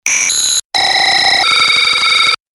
Scifi звук экрана с ошибкой